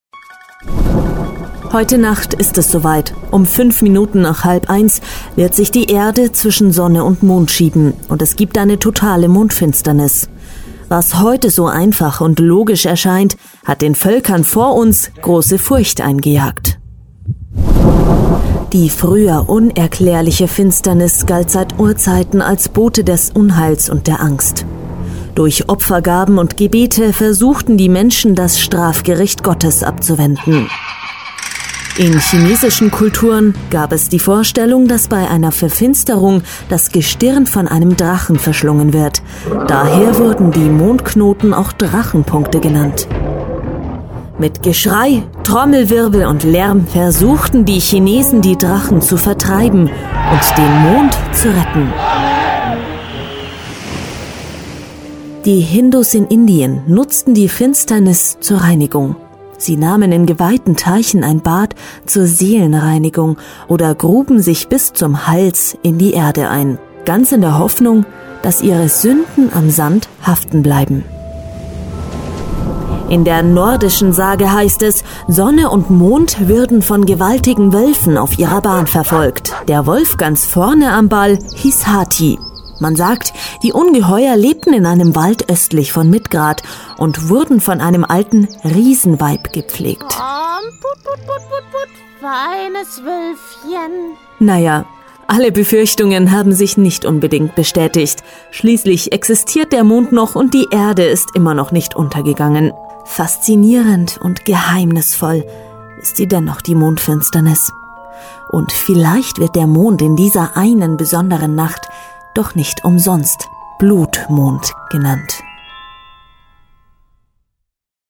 Sprechprobe: Werbung (Muttersprache):
female voice over artist german